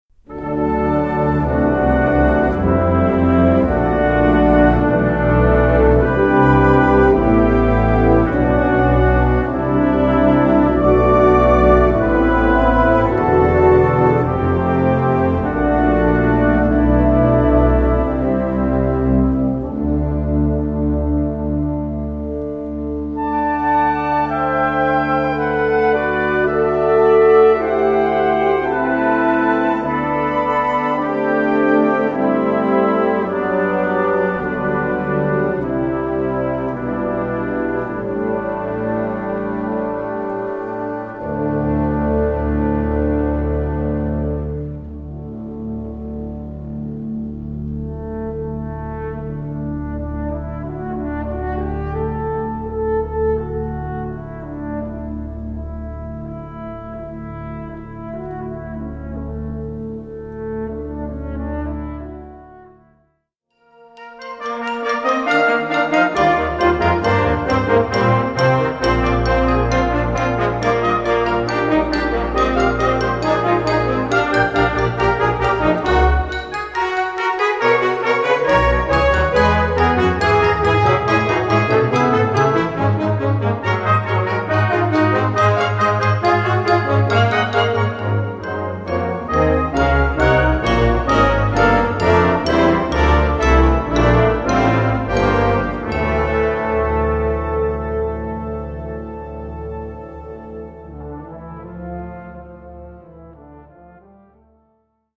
Gattung: Solo für Blechbläserquintett und Blasorchester
Besetzung: Blasorchester